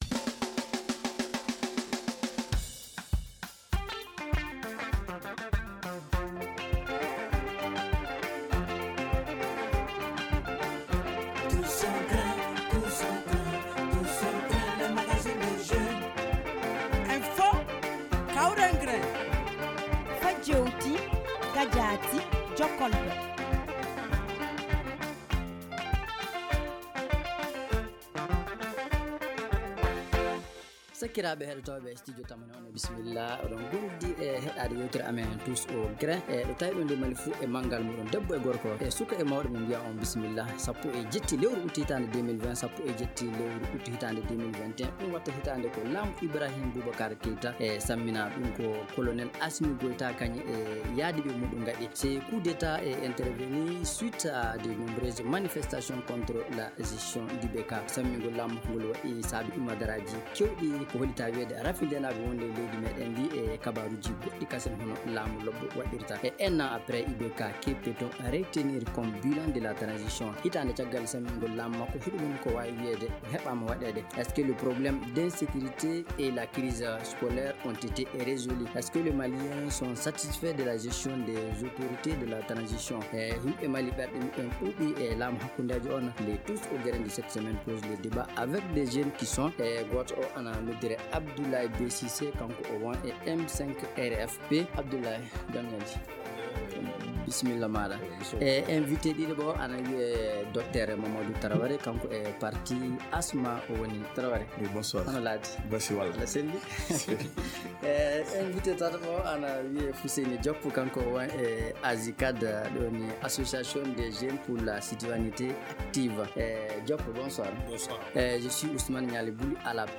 Les maliens sont-ils satisfaits de la gestion du pouvoir les autorités de transition ?Le tous au grin de cette semaine pose le débat.